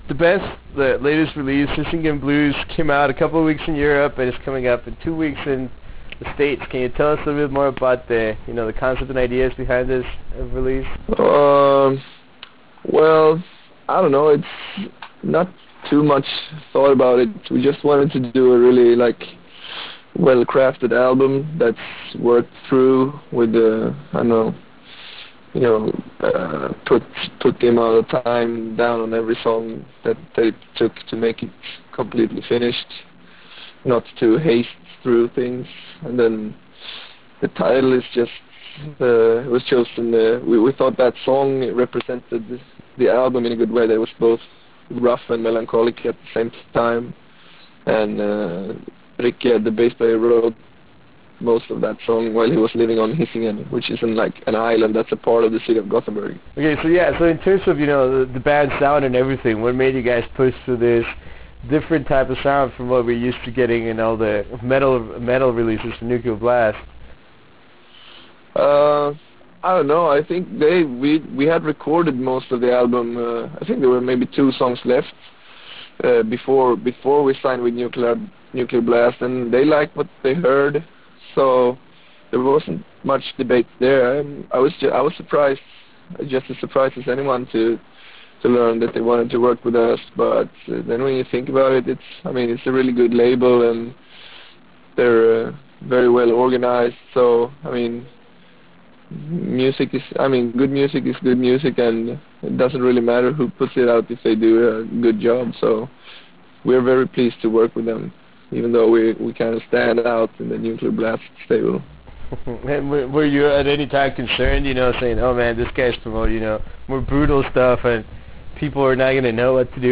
In this interview we discuss the album and its enigmatic cover; we also talk about future tour dates in North America.